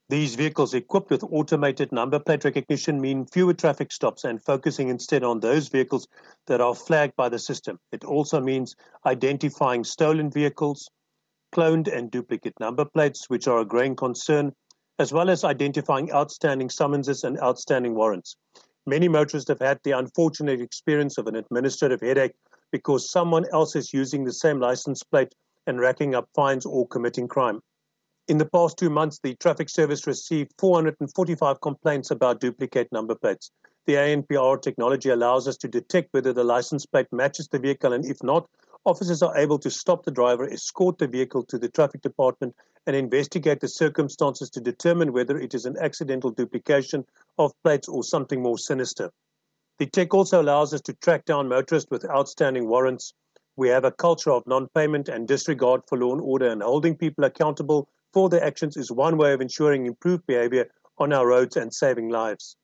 May-co member at the Directorate, JP Smith says, in the past two months alone traffic services received 445 complaints about duplicated number plates.